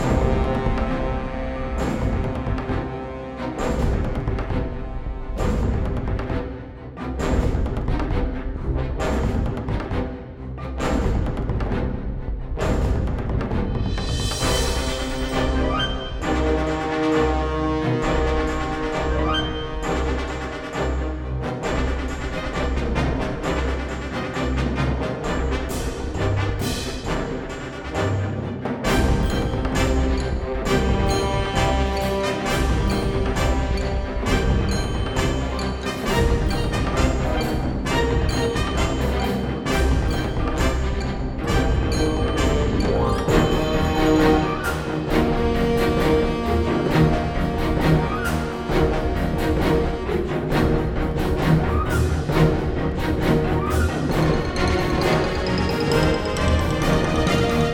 Combat (loop):
GameMusicWorkshop_Uncharted-Combat_sum.mp3